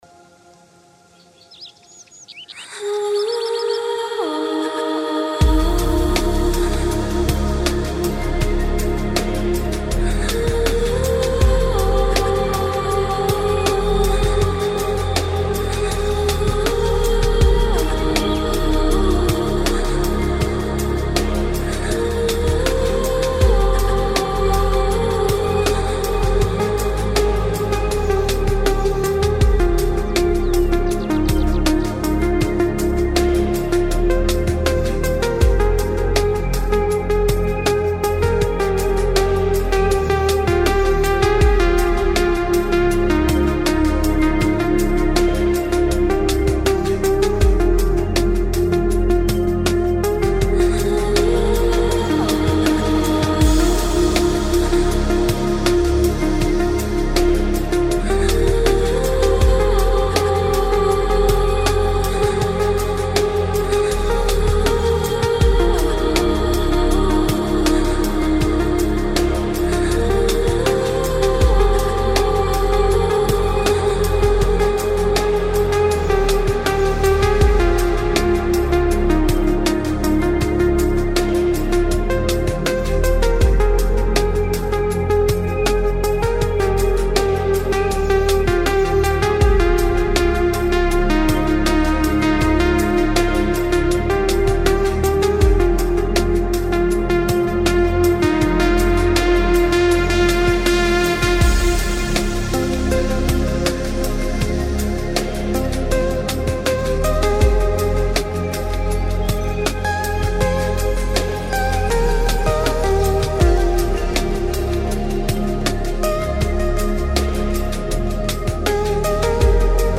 یه موسیقی شاهکارو تلفیقی از سبکای ambient-chillout-ambient
ambient-chillout-ambient-mix-14.mp3